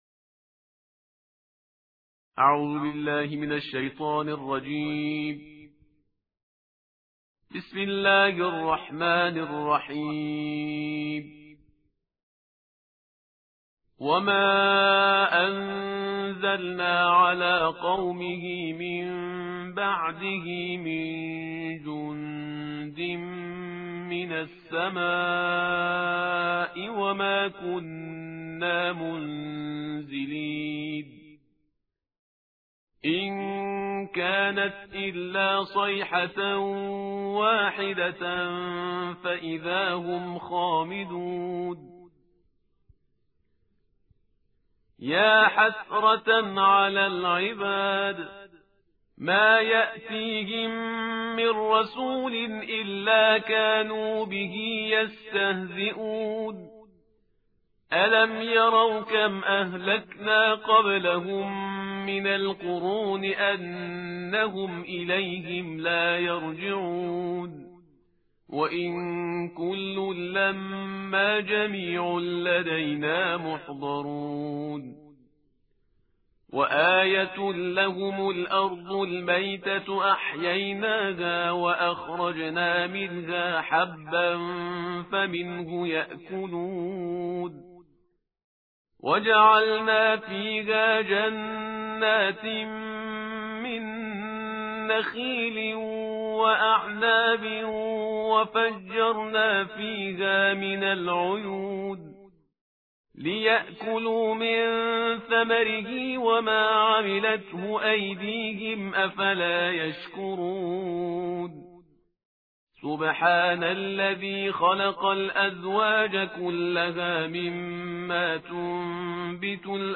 ترتیل جزء «23» قرآن کریم به نیابت از شهدای هنرمند استان لرستان